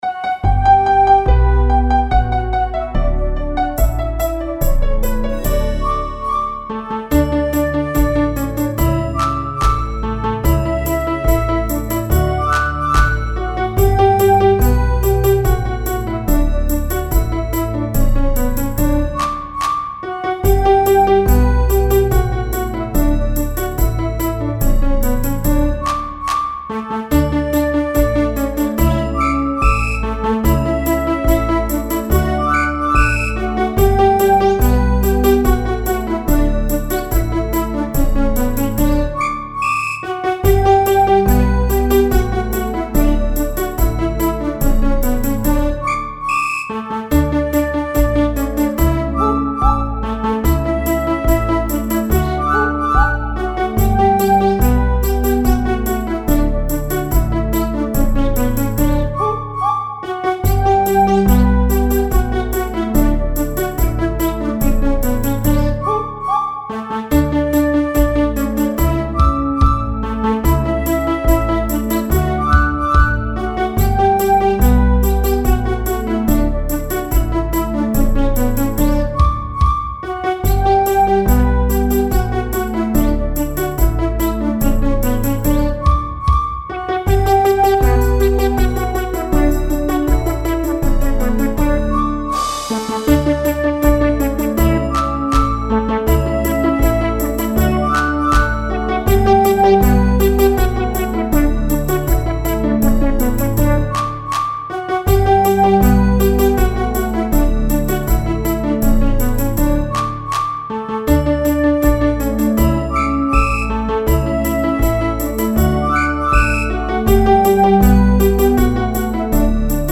Canción Infantil, EEUU
incluye un cambio de tempo a mitad de la canción
karaoke